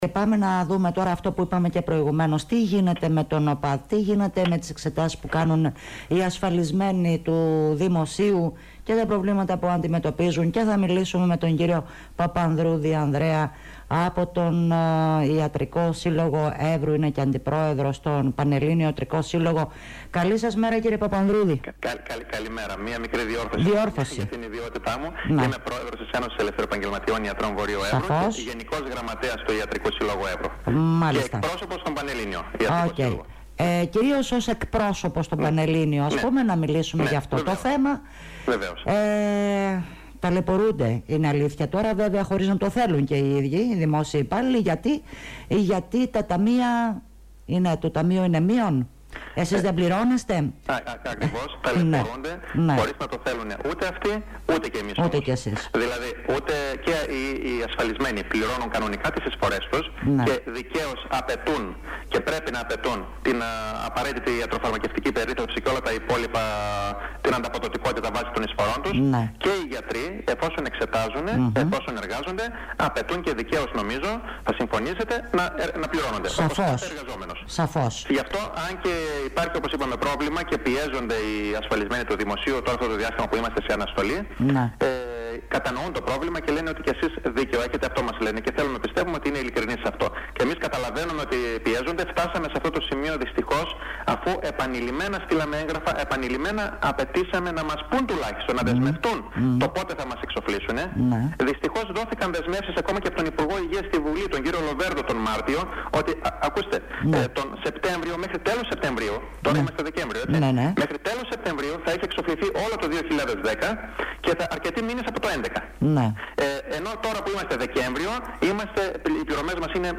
μίλησε στο Ράδιο Έβρος